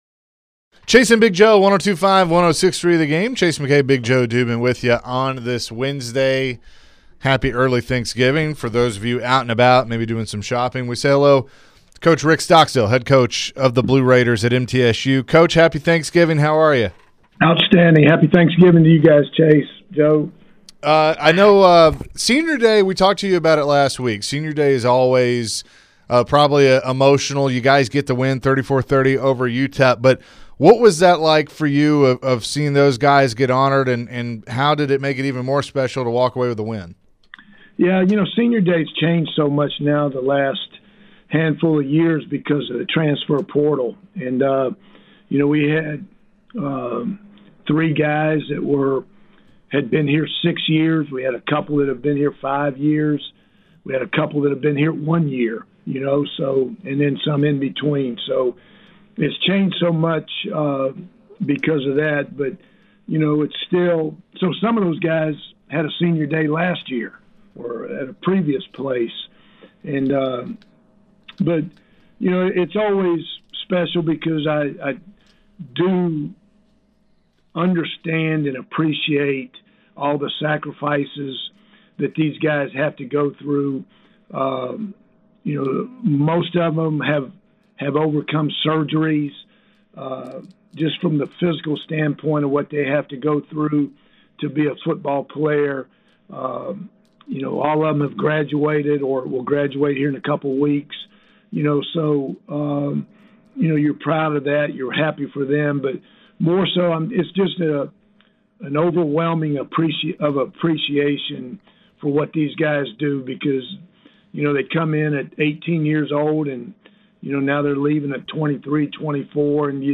talked with MTSU head football coach Rick Stockstill